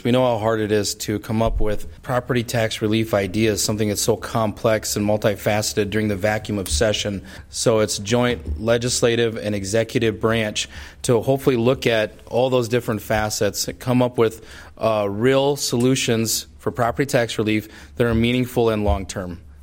Sioux Falls Republican Senator Chris Karr.